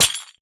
Minecraft Version Minecraft Version 1.21.5 Latest Release | Latest Snapshot 1.21.5 / assets / minecraft / sounds / block / chain / break1.ogg Compare With Compare With Latest Release | Latest Snapshot
break1.ogg